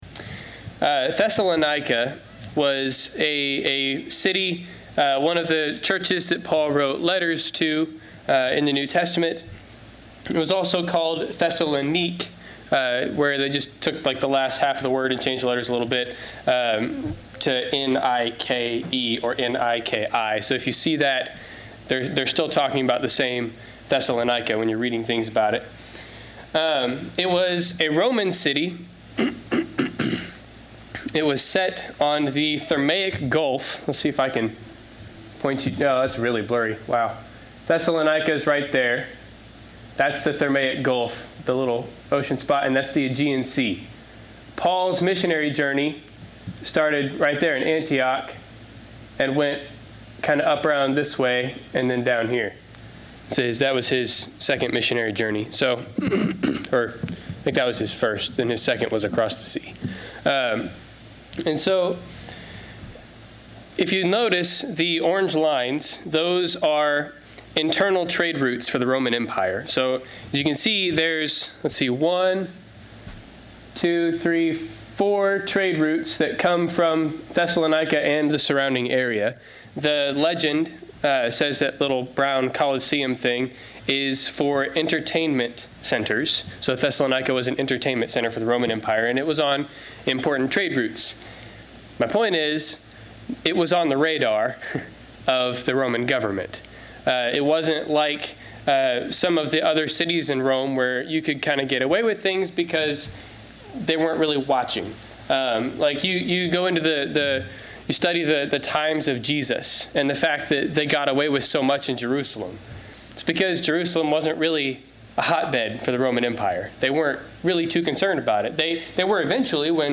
Passage: 1 and 2 Thessalonians Service Type: Sunday 10:00 AM Topics